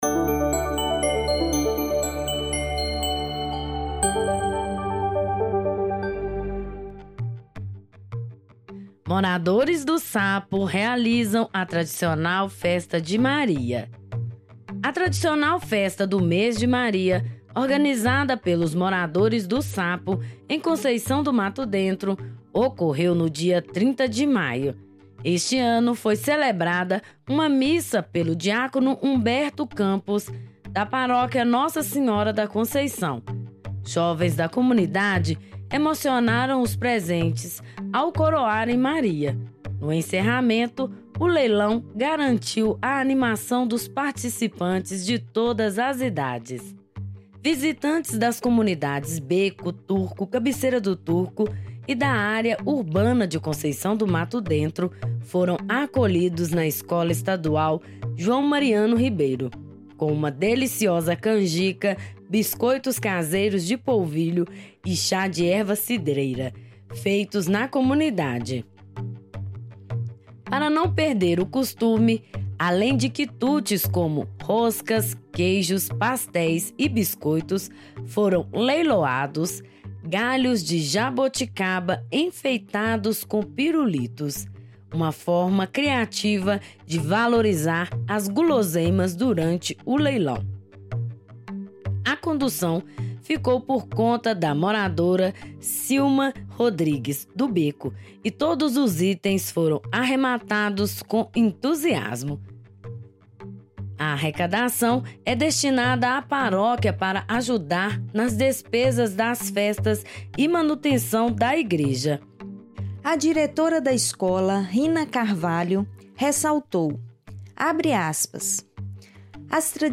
A tradicional Festa do mês de Maria, organizada pelos moradores do Sapo, em Conceição do Mato Dentro, ocorreu no dia 30 de maio.